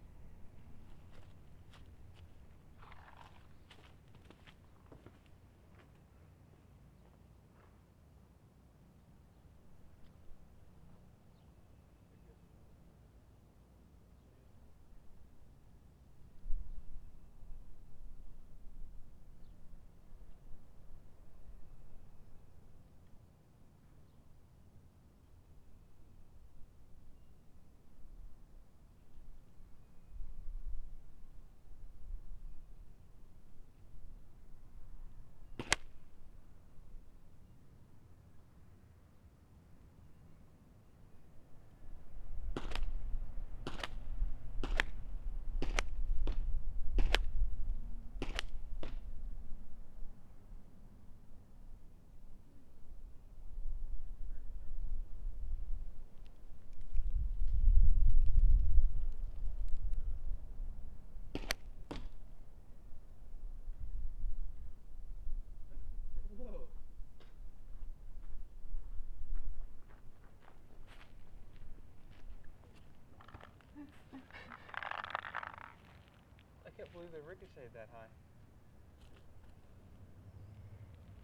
splats_dirt_ricochet..>2009-02-22 20:57 7.4M